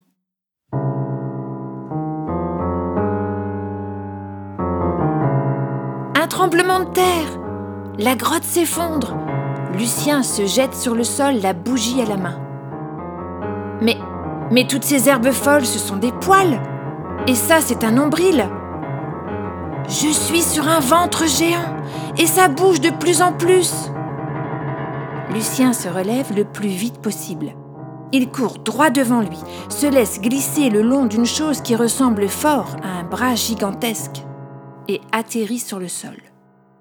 Au son des hautbois, piano, sonnette hollandaise, noix de coco, ocean drum, tambourin, cor anglais, grelots, flûte irlandaise, il part en quête d’un sapin.
Tout en musique (Saint Saens, Weber, Bizet, mais aussi des morceaux traditionnels et des compositions originales), ce conte est construit autour de la différence, de l’écoute bienveillante, de la découverte et de l’entraide.